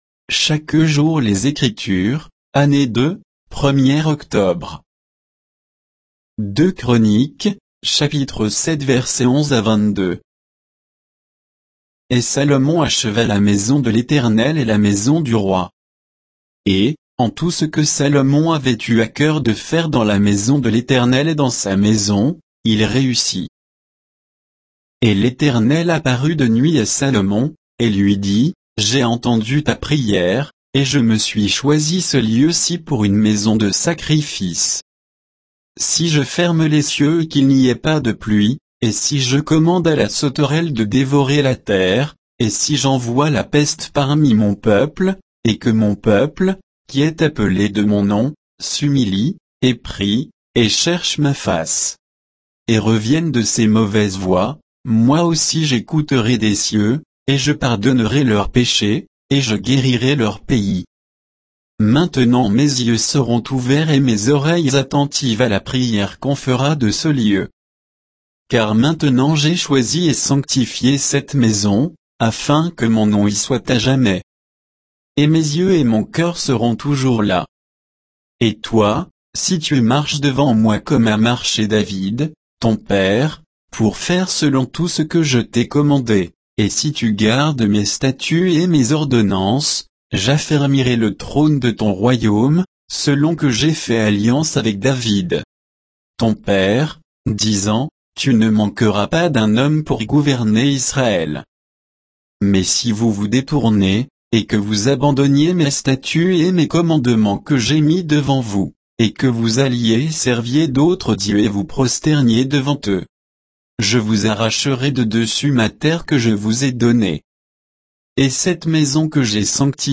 Méditation quoditienne de Chaque jour les Écritures sur 2 Chroniques 7, 11 à 22